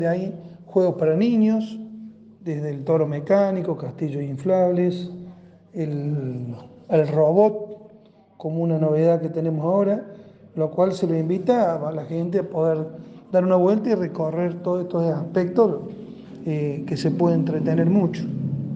En comunicación con nuestro portal de noticias el intendente Pablo alcalino nos decía los siguiente: